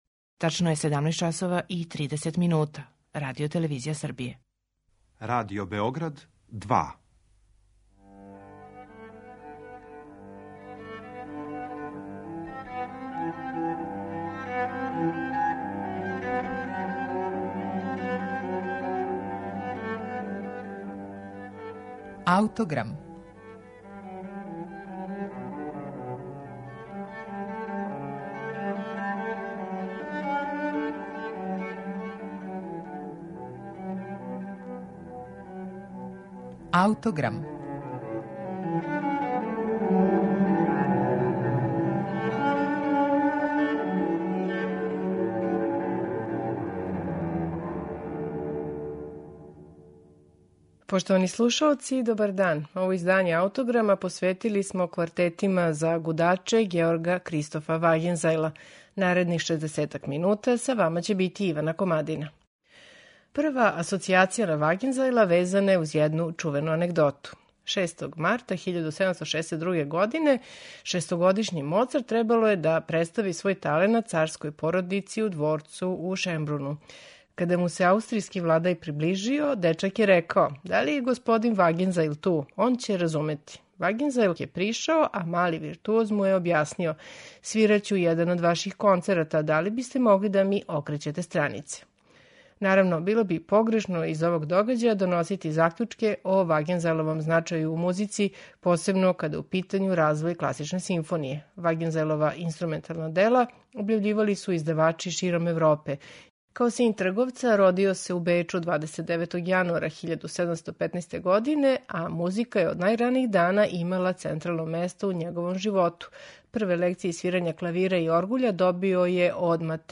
Шест соната за три виолончела и контрабас, или шест квартета за, како се на манускрипту наводи "ниске гудаче", Георга Кристофа Вагензајла, заузимају сасвим издвојено место у опусу камерне музике овог аустријског аутора. Ови квартети су сасвим јединствени по извођачком саставу, а потом и четвороставачној форми која наговештава облик класичног гудачког квартета.
Чућете их на снимку, који су остварили чланови ансамбла Piccolo Concerto Wien .